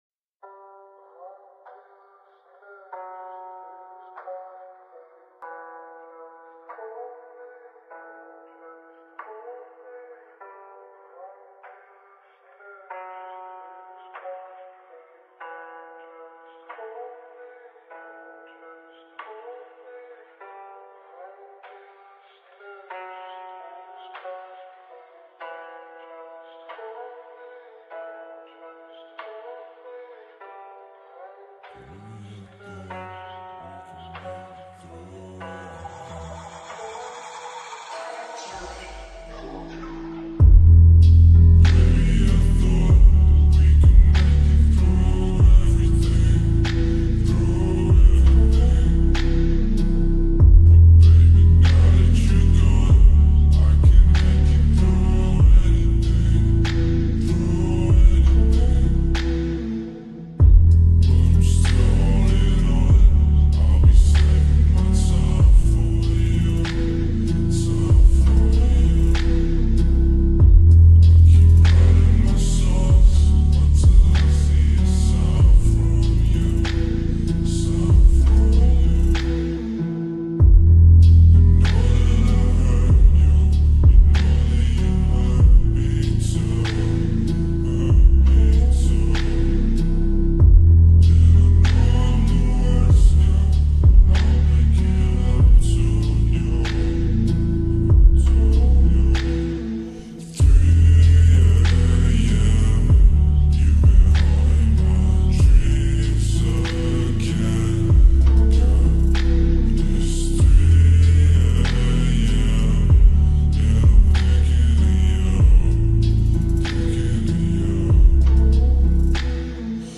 ورژن آهسته کاهش سرعت